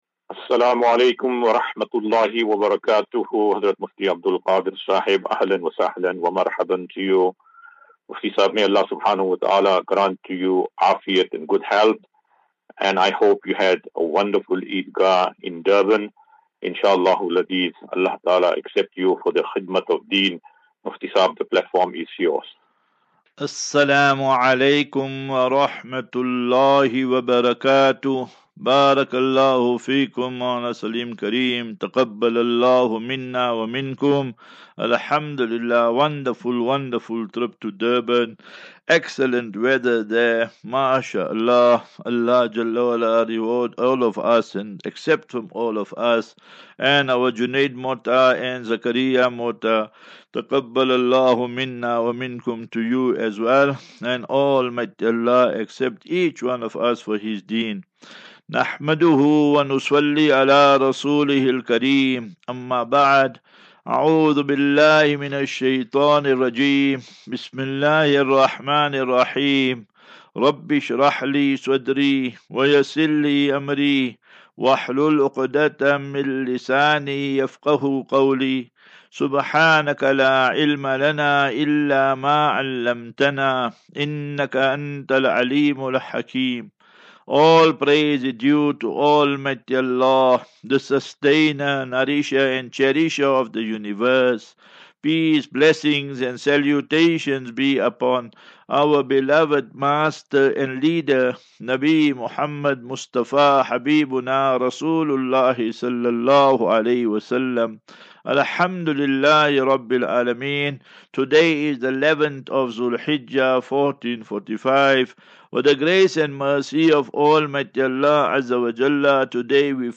18 Jun 18 June 2024. Assafinatu - Illal - Jannah. QnA